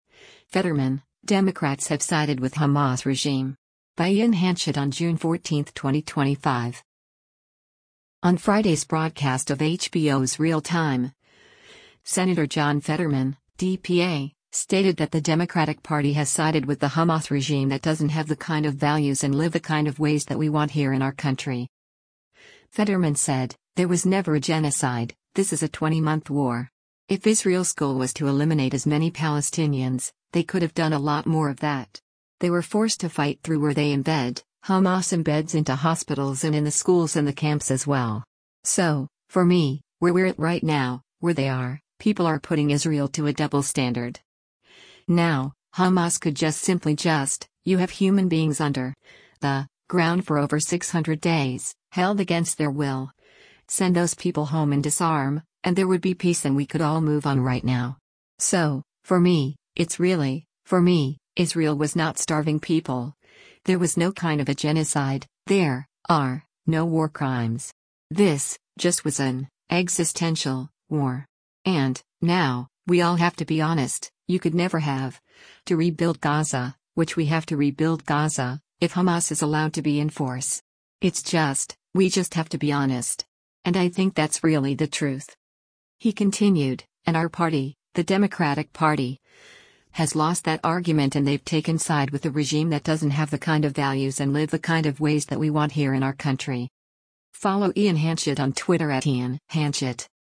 On Friday’s broadcast of HBO’s “Real Time,” Sen. John Fetterman (D-PA) stated that the Democratic Party has sided with the Hamas regime “that doesn’t have the kind of values and live the kind of ways that we want here in our country.”